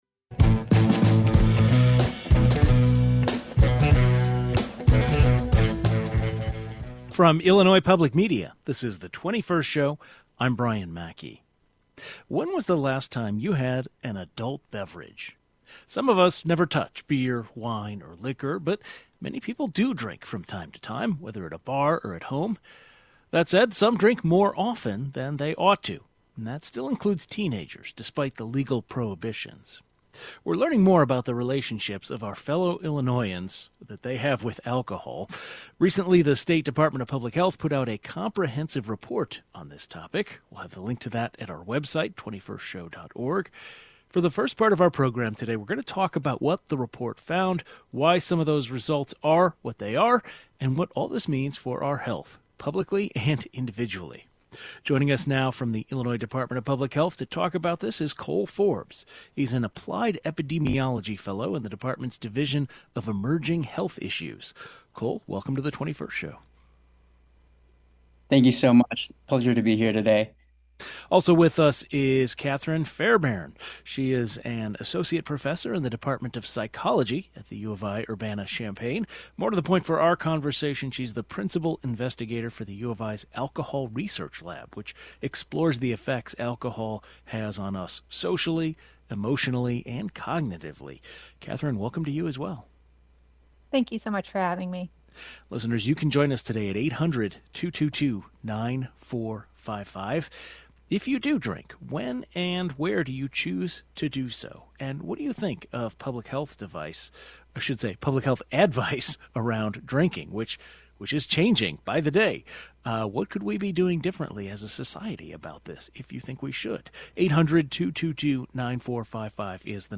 The 21st Show is Illinois' statewide weekday public radio talk show, connecting Illinois and bringing you the news, culture, and stories that matter to the 21st state.
Last month, the state Department of Public Health put out a comprehensive report on alcohol use in Illinois. The report investigates the relationships Illinoisans have with alcohol. A public health expert and an alcohol researcher discuss the findings and what they mean for our health.